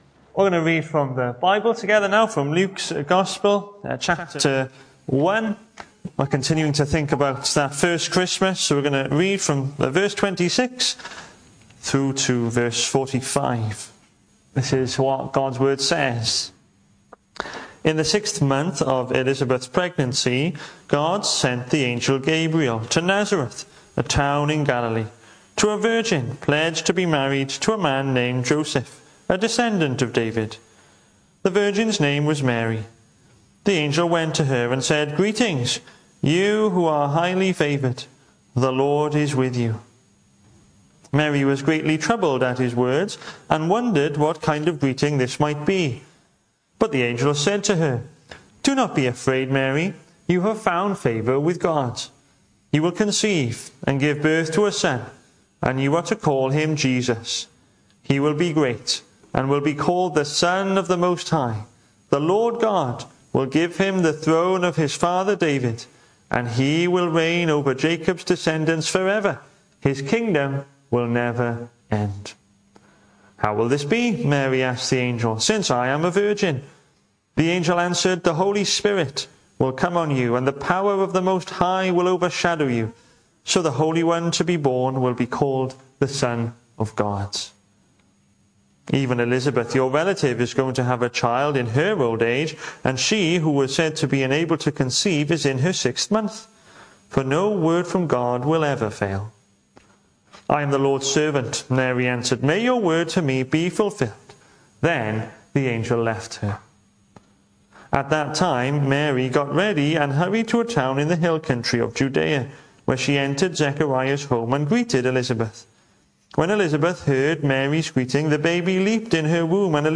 The 8th of December saw us host our Sunday morning service from the church building, with a livestream available via Facebook.
Weekly Sermons